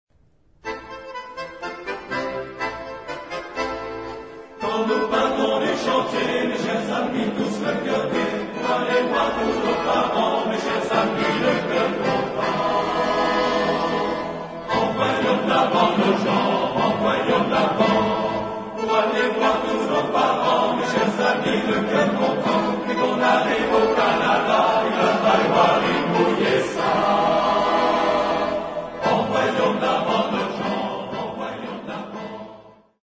Chanson canadienne de pagayeurs et de draveurs ...
Genre-Style-Form: Secular ; Popular ; Sailors' song ; Song with repetition
Type of Choir: TTBarB  (4 men voices )
Soloist(s): Ténor (ad libitum)  (1 soloist(s))
Tonality: A minor